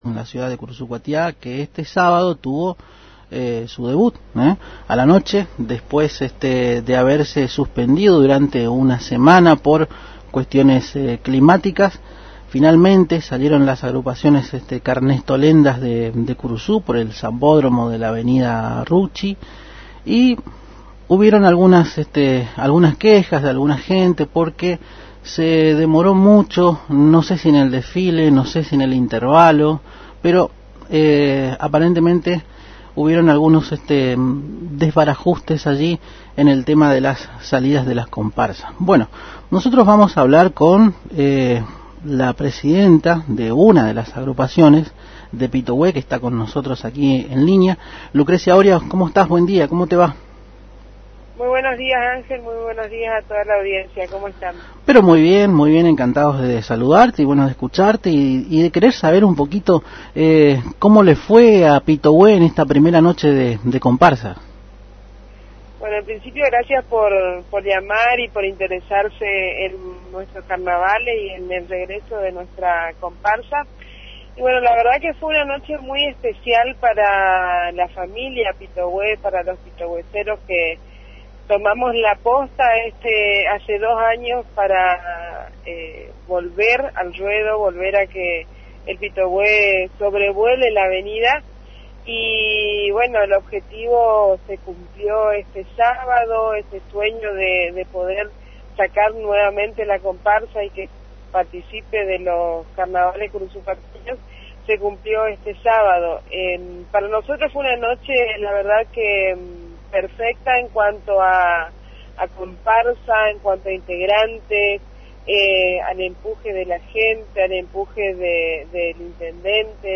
En di�logo con Arriba Ciudad a trav�s de la AM 970 Radio Guarani